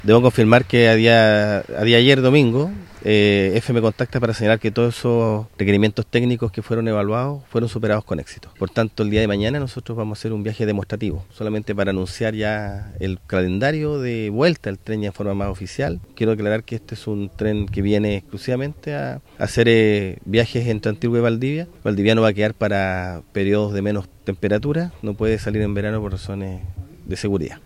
En conversación con La Radio, Alvial confirmó que las pruebas fueron exitosas y este martes se anunciarán las fechas para volver a abordar los vagones.